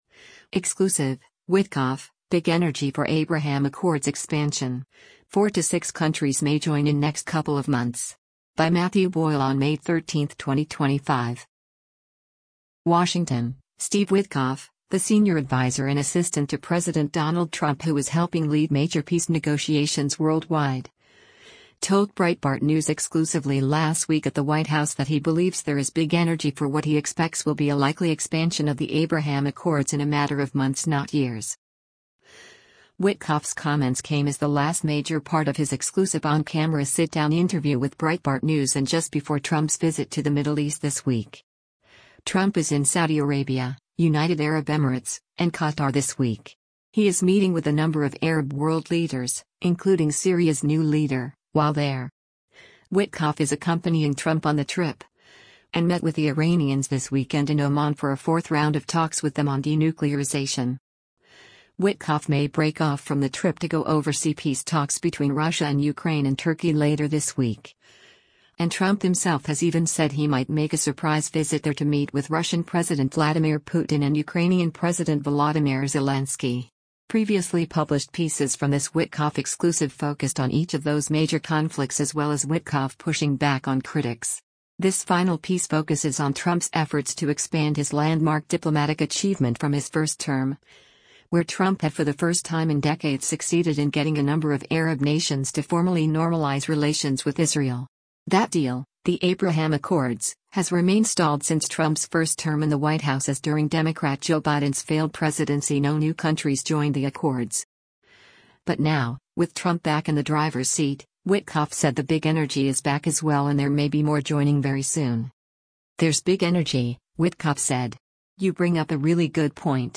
Witkoff’s comments came as the last major part of his exclusive on-camera sit-down interview with Breitbart News and just before Trump’s visit to the Middle East this week.